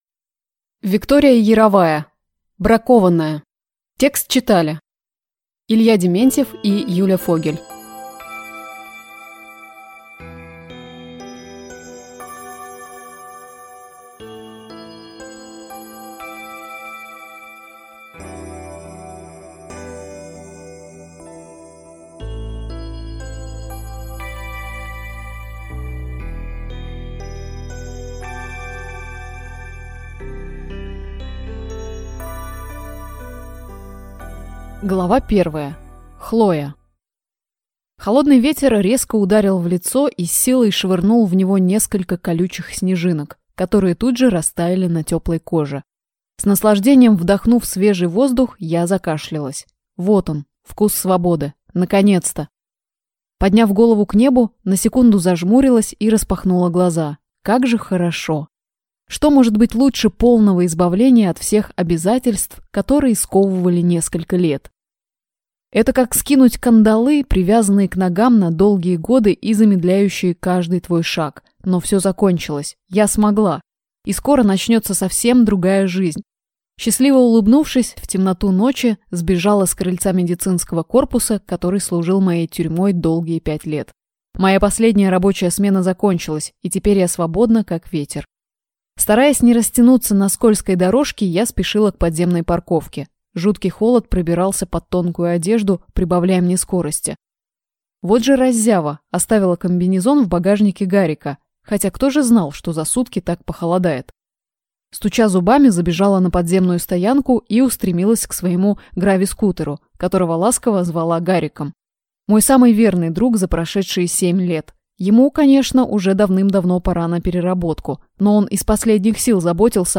Аудиокнига Бракованная | Библиотека аудиокниг